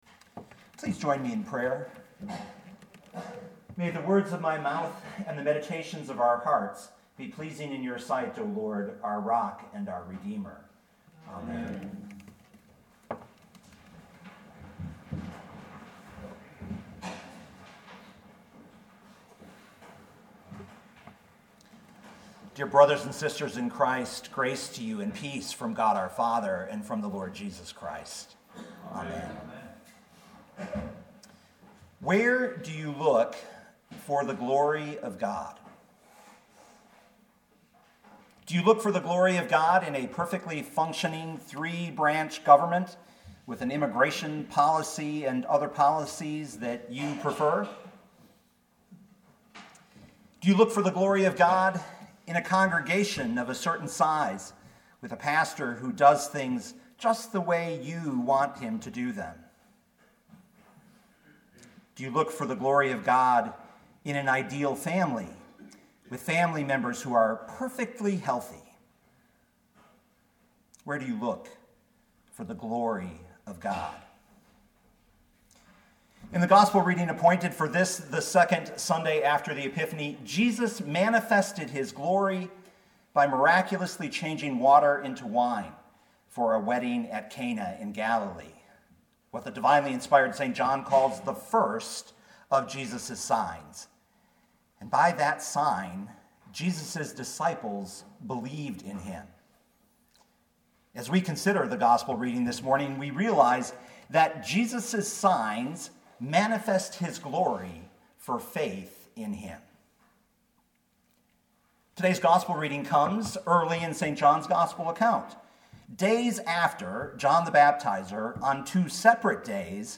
2019 John 2:1-11 Listen to the sermon with the player below, or, download the audio.